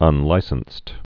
(ŭn-līsənst)